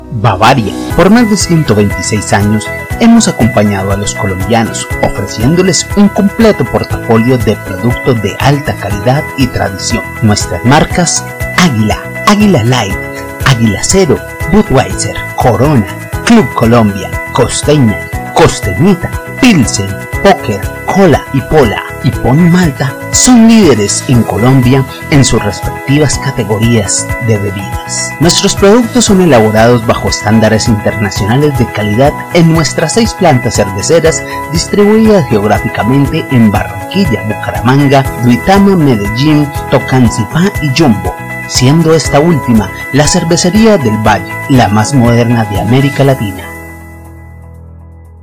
spanisch Südamerika
kolumbianisch
Sprechprobe: Industrie (Muttersprache):